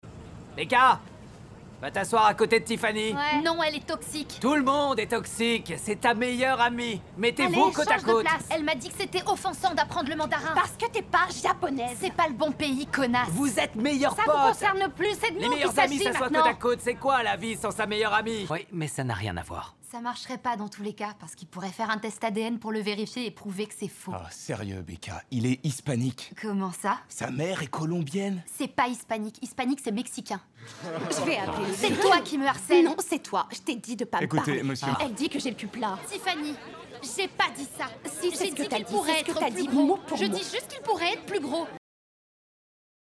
Doublage - Becca (lycéenne) - Netflix - English Teacher
Je suis appréciée dans la Pub, les films institutionnels, le jeu vidéo, la narration et ma voix est parfois douce, parfois dynamique et chaleureuse.
5 - 40 ans - Mezzo-soprano